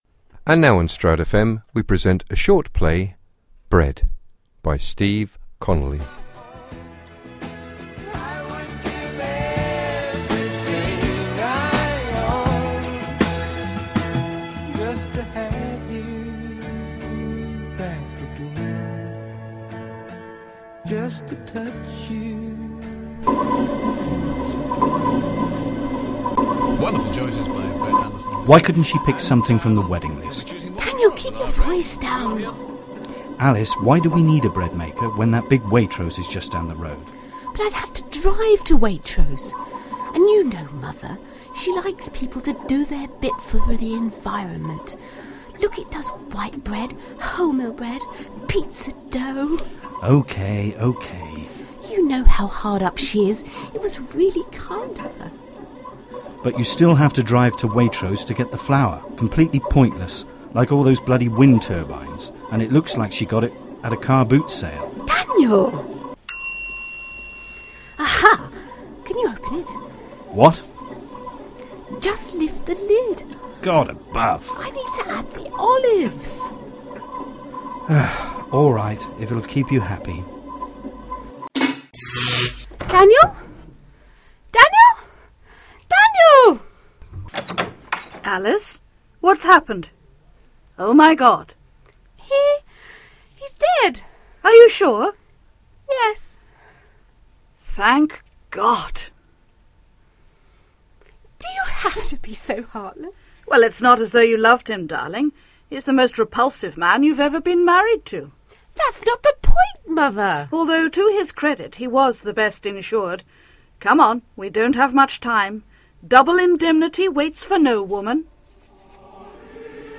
Bread: A Dark Comedy
As part of this, we have each written a 10-15 minute script to be recorded and broadcast.